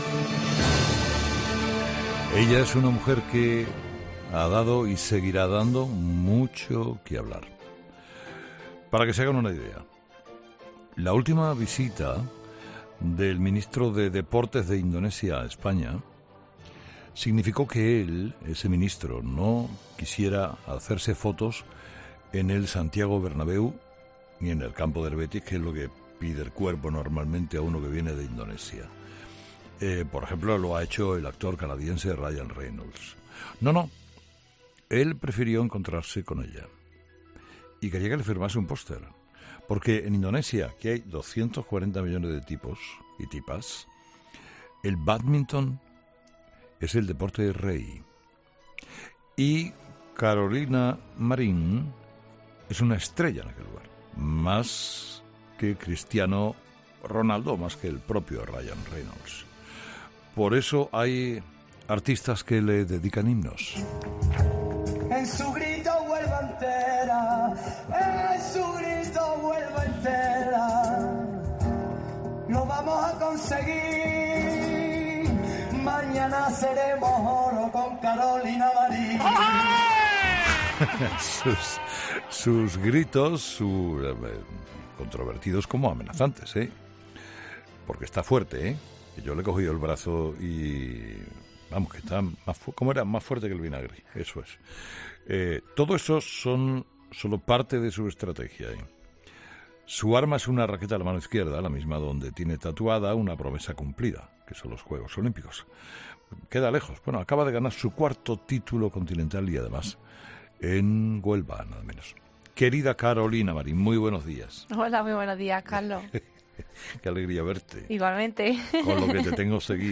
La campeona de bádminton, Carolina Marín, en Herrera en COPE